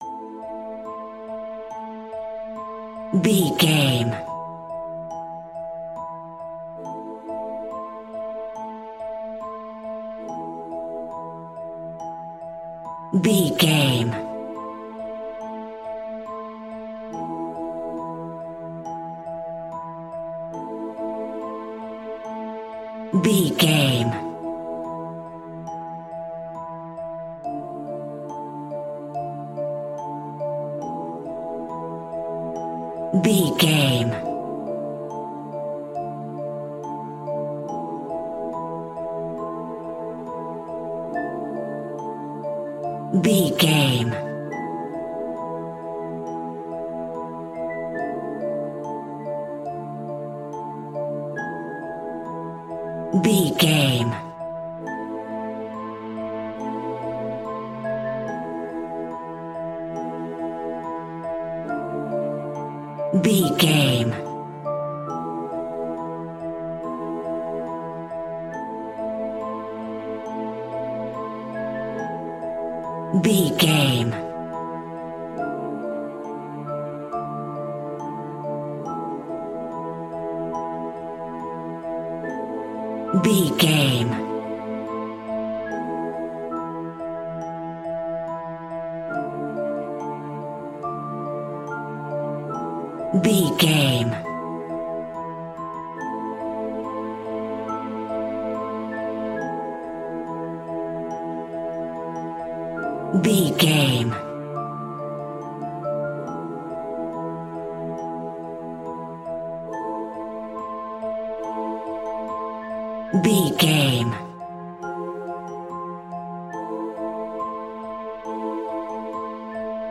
Horror Music for Children and Kids.
Aeolian/Minor
ominous
haunting
eerie
piano
strings
horror music